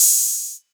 Open Hat [12].wav